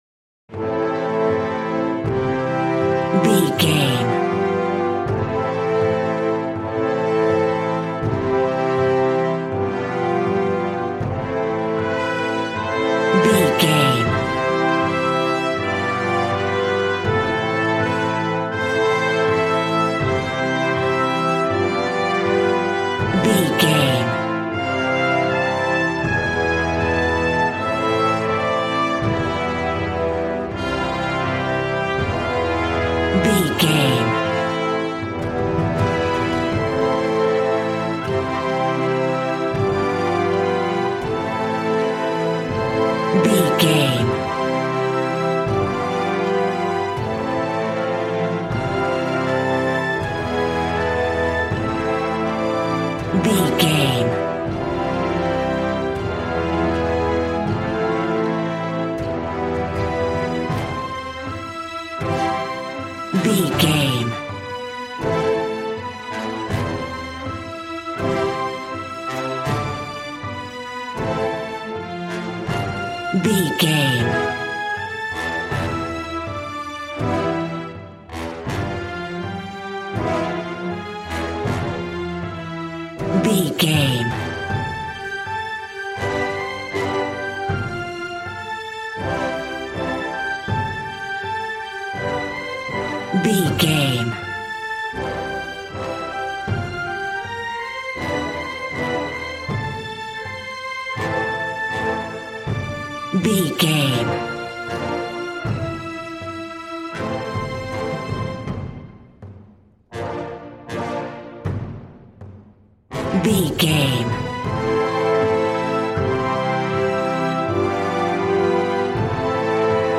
Aeolian/Minor
brass
strings
violin
regal